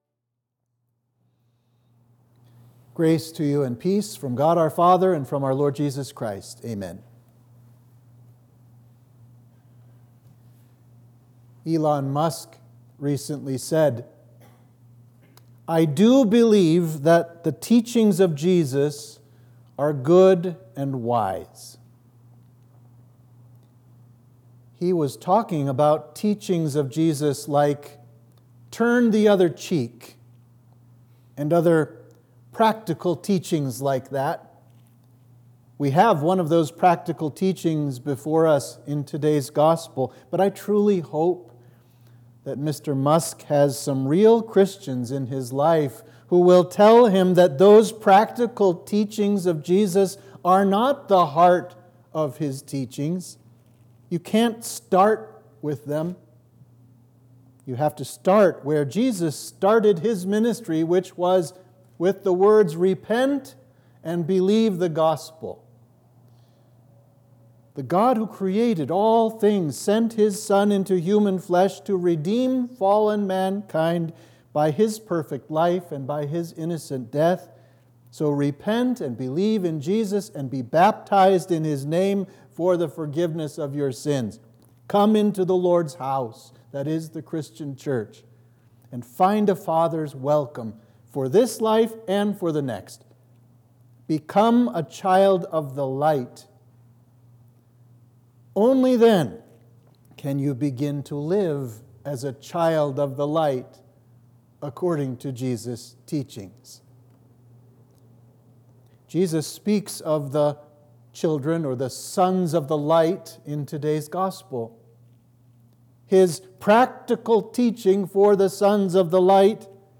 Sermon for Trinity 9